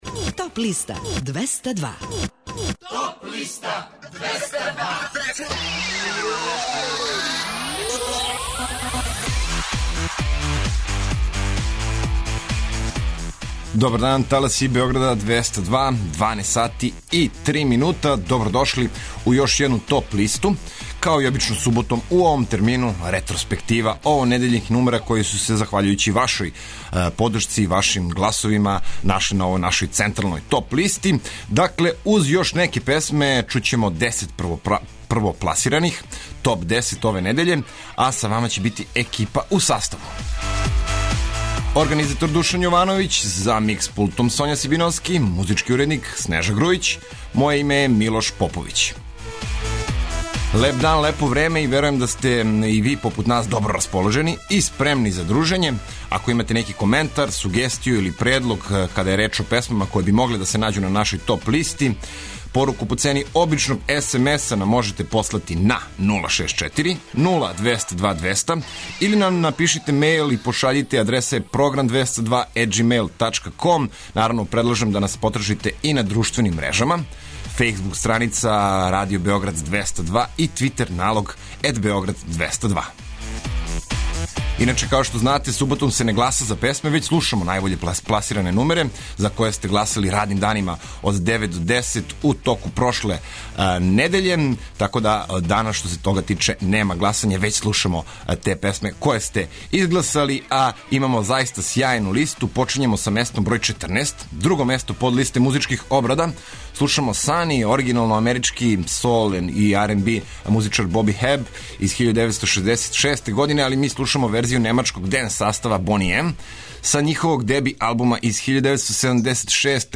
Чујте и композиције које су се нашле на подлисти лектира, класика, етно, филмска музика...